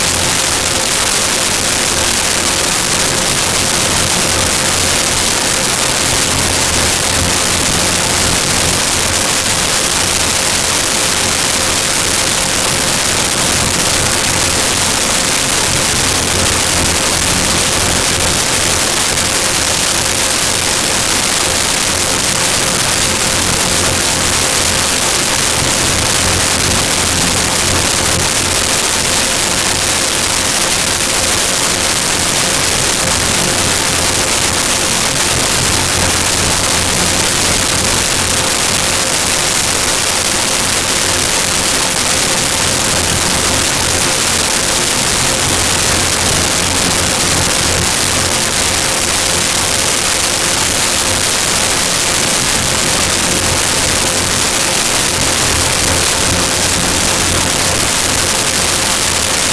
Tension Call With Clock
Tension_call_with_clock.wav